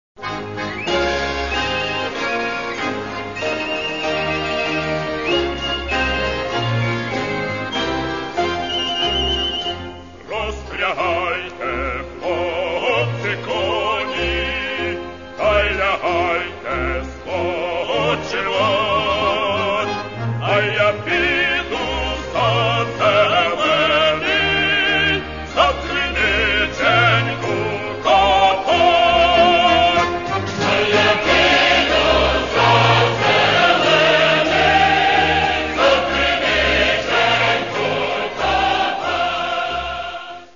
Catalogue -> Folk -> Traditional Solo Singing and Choirs